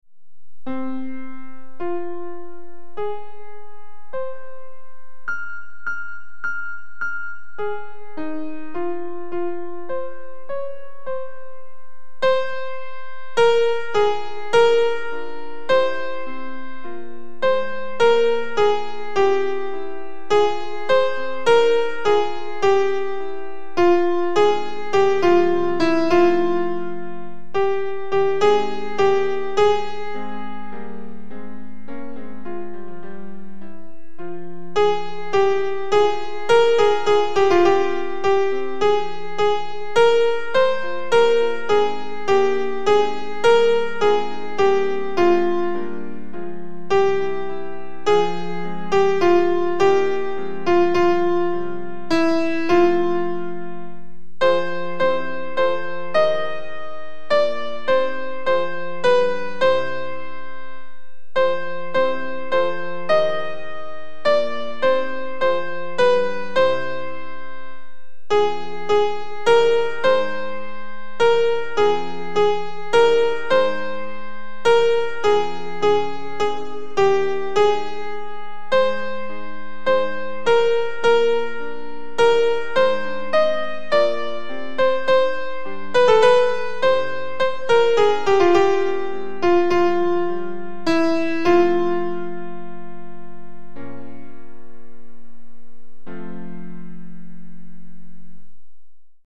Sound – Soprano.mp3
Victoria_Soprano.mp3